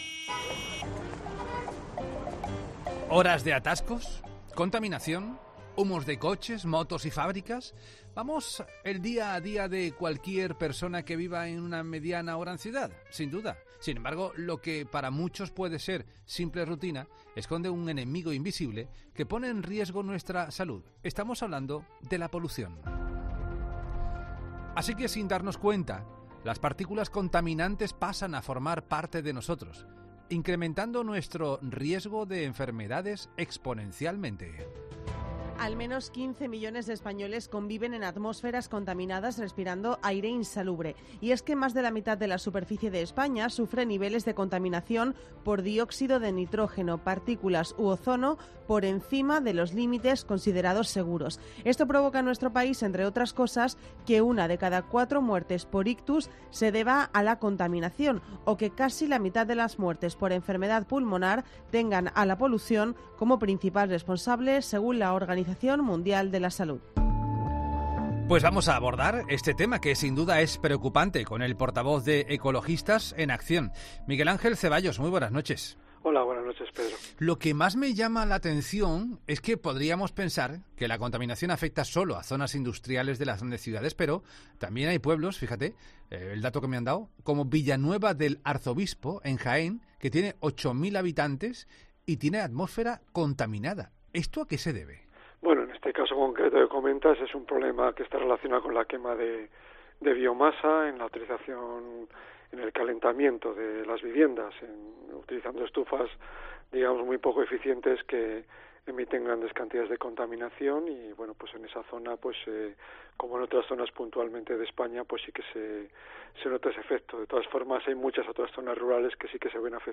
Un experto de Ecologistas en Acción explica en 'La Noche de COPE' cómo nos afecta la contaminación que sufren en mayor medida las grandes ciudades.